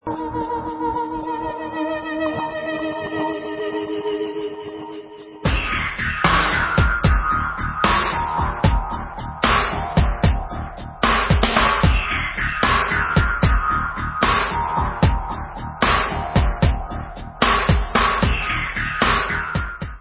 Synthie-pop